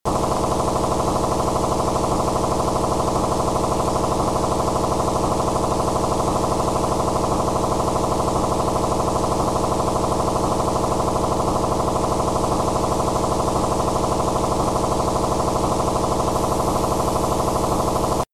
В NXDN применяется FSK ЧМн модуляция в полосе 6.25 кГц или 12.5 кГц.
Образцы сигнала NXDN™
nxdn_trunking_channel.mp3